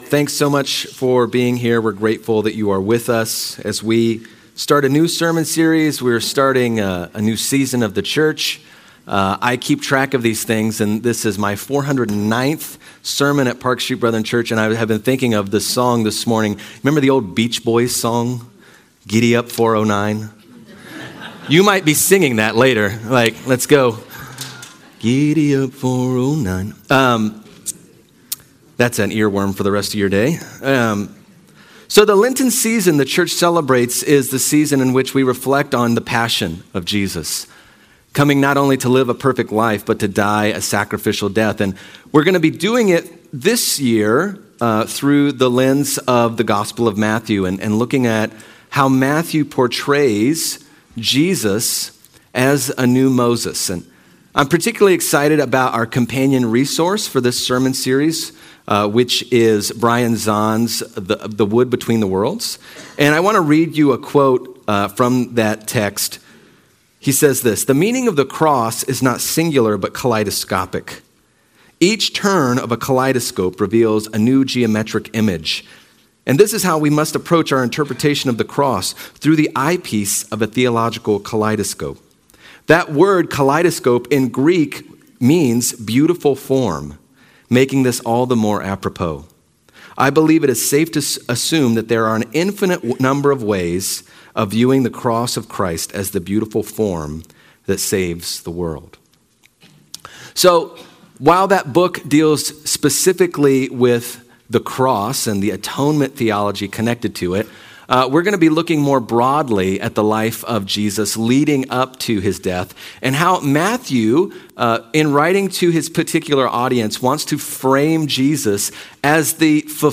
Sermons - Park Street Brethren Church
Sermon Series